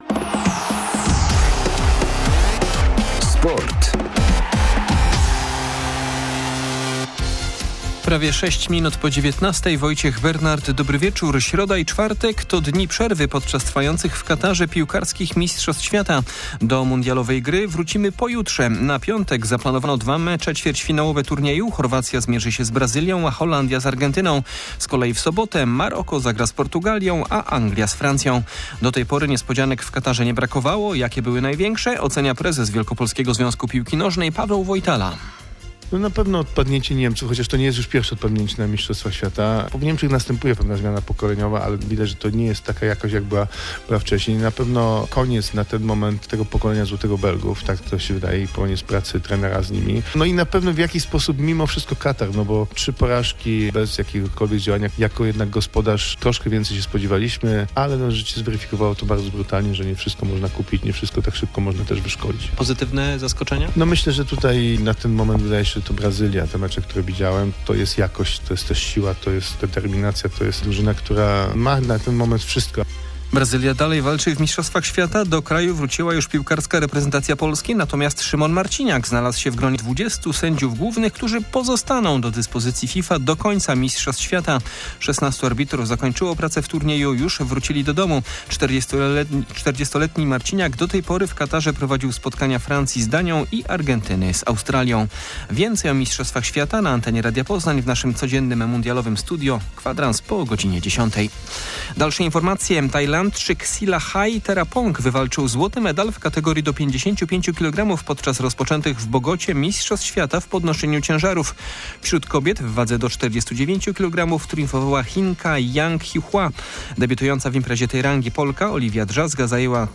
07.12.2022 SERWIS SPORTOWY GODZ. 19:05